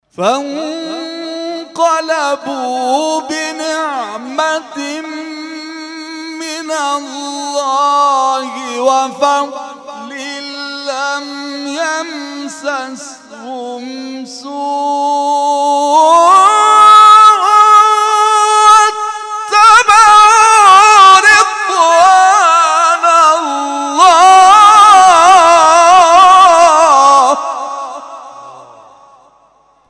محفل انس با قرآن در آستان عبدالعظیم(ع) + صوت